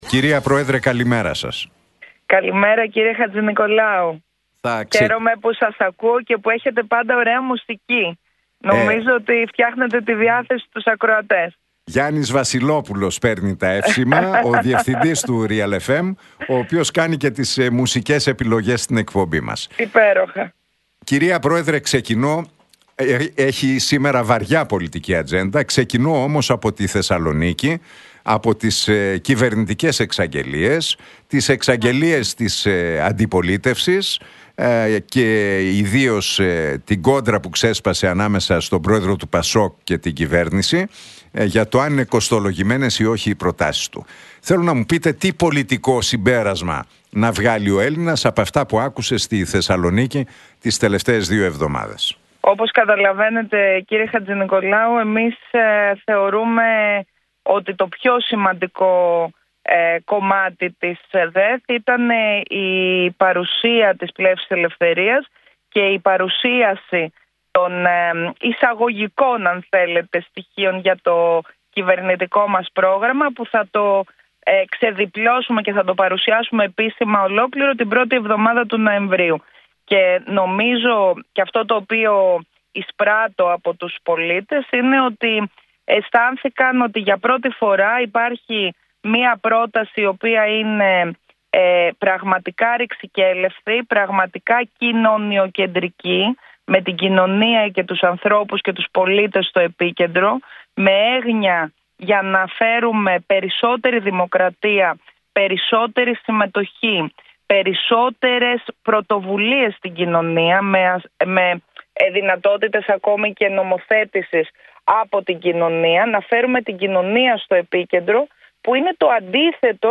Η Ζωή Κωνσταντοπούλου, πρόεδρος της Πλεύσης Ελευθερίας, σε συνέντευξή της στον Realfm 97,8, εξέφρασε έντονη κριτική προς την κυβέρνηση Μητσοτάκη,